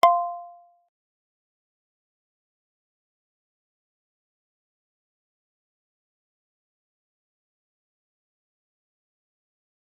G_Kalimba-F6-mf.wav